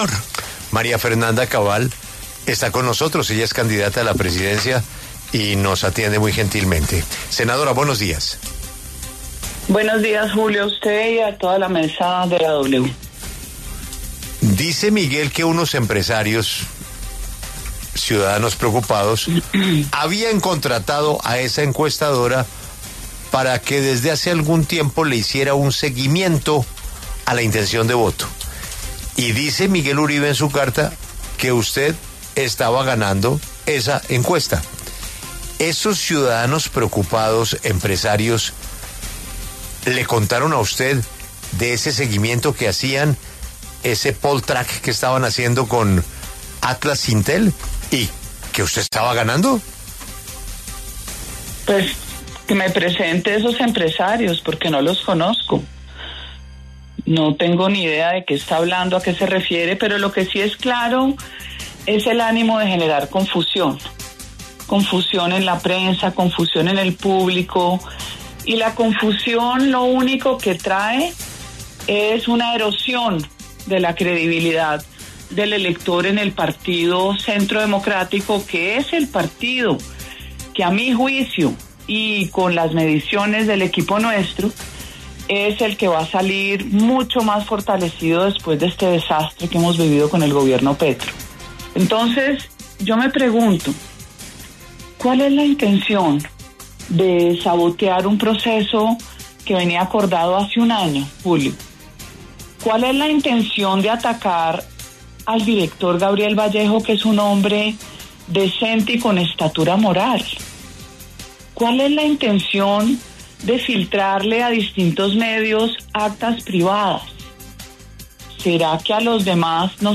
La senadora y precandidata María Fernanda Cabal pasó por los micrófonos de La W y se refirió a las tensiones internas en el Centro Democrático, pero también a las presuntas presiones de Uribe Londoño sobre la firma Atlas Intel